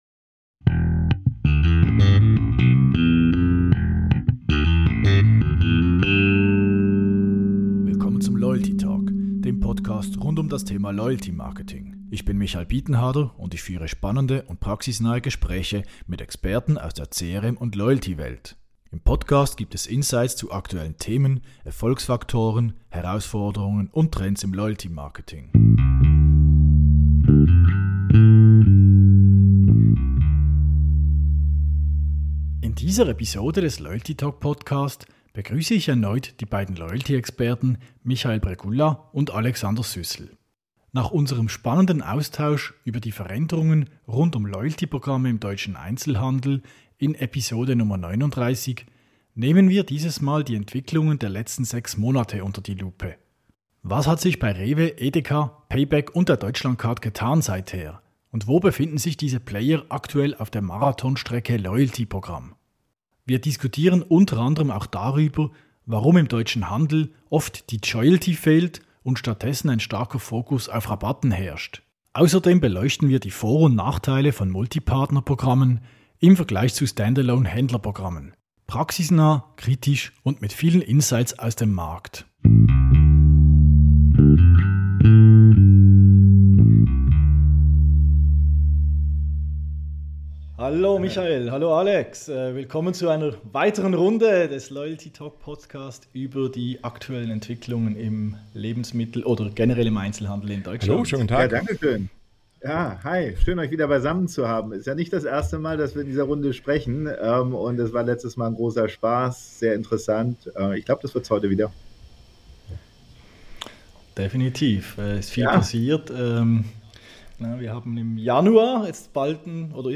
Der Loyalty Talk ist ein Podcast rund um das Thema Loyalty-Marketing. Aufschlussreiche und praxisnahe Gespräche mit Experten aus der CRM- und Loyalty-Welt und Insights zu aktuellen Themen, Erfolgsfaktoren, Herausforderungen und Trends im Loyalty-Marketing.